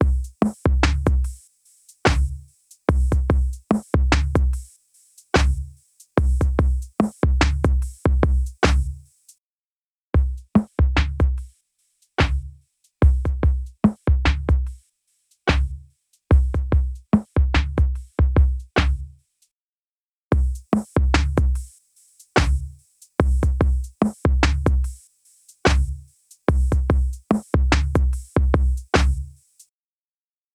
EChannel | Drum Machine | Preset: Toasty Limiter